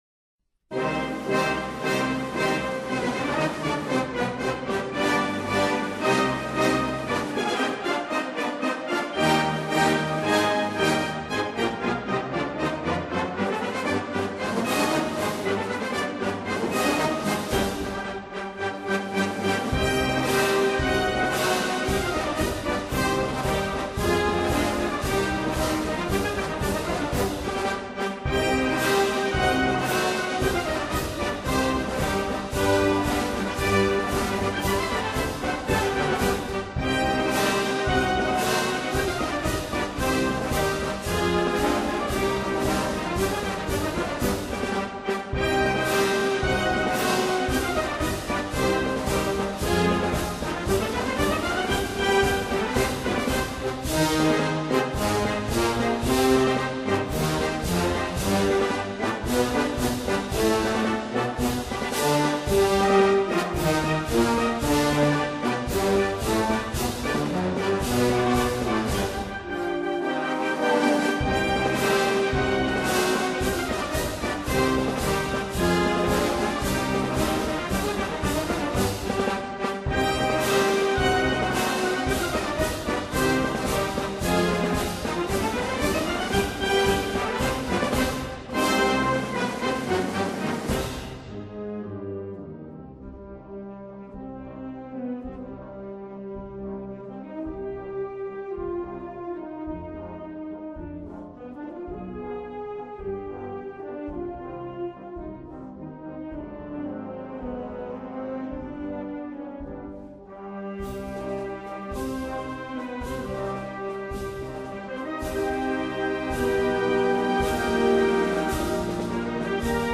est une puissante marche symphonique sonore et majestueuse.
Brass Band
Marches